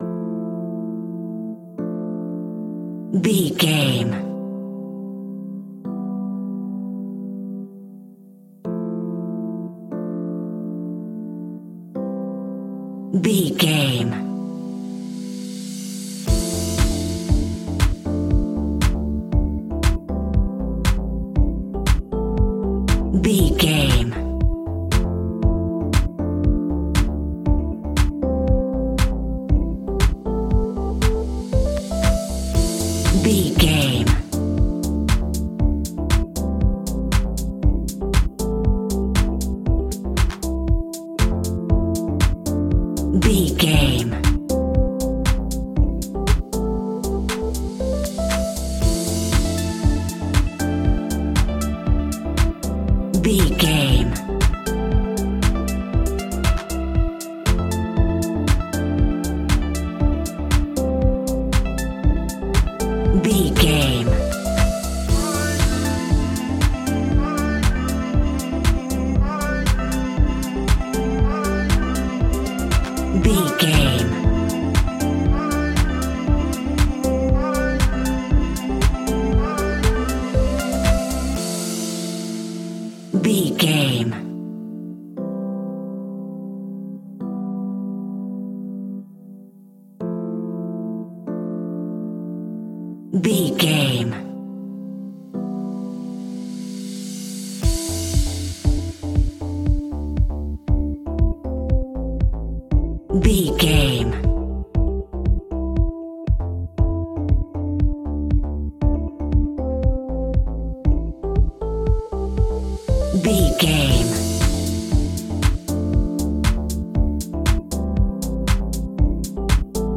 Ionian/Major
groovy
uplifting
driving
energetic
bouncy
electric piano
synthesiser
drum machine
vocals
electronic